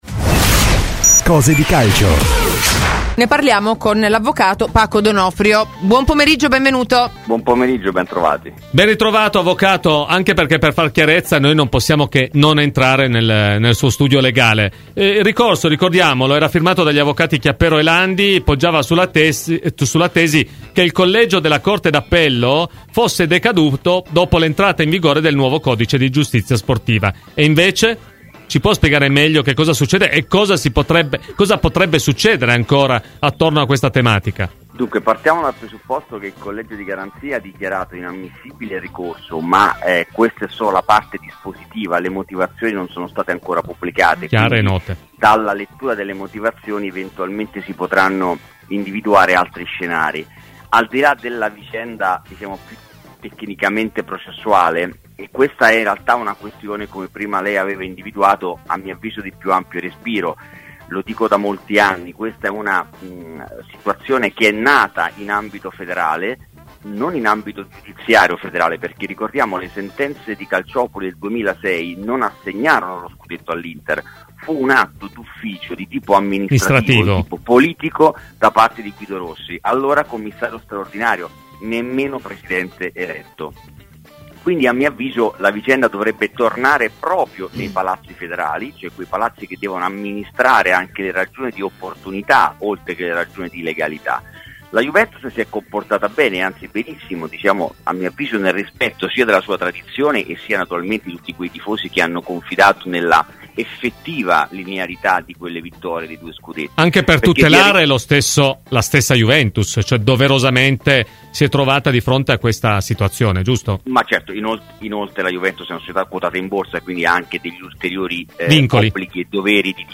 © registrazione di Radio Bianconera